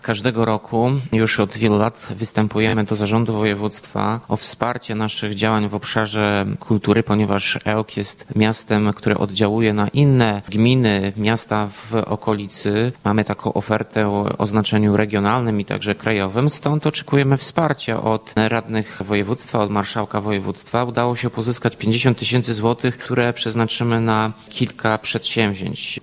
Mówi Tomasz Andrukiewicz, prezydent Ełku.